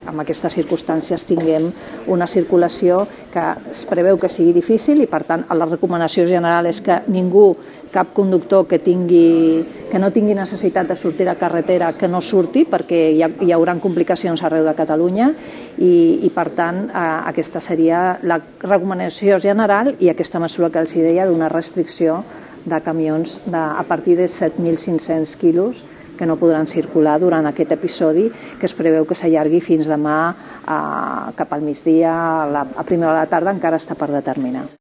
Eugènia Domènech és la directora del Servei Català de Trànsit: